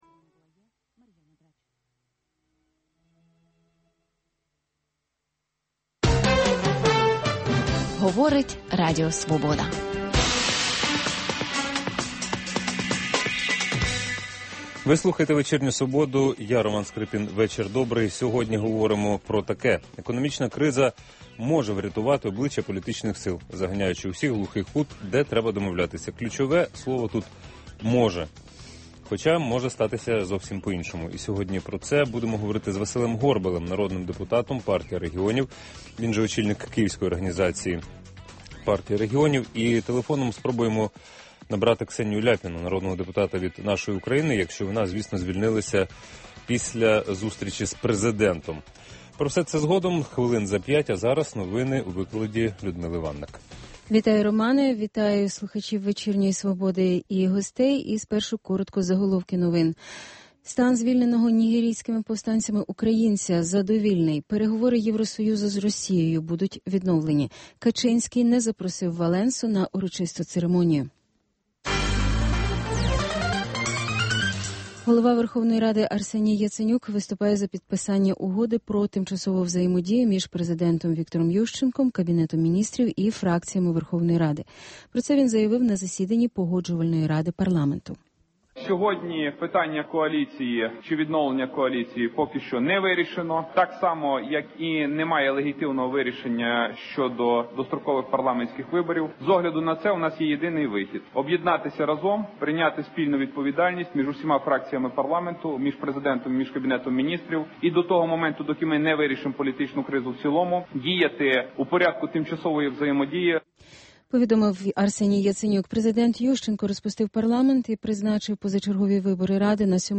Інтелектуальна дуель у прямому ефірі. Дискусія про головну подію дня, що добігає кінця.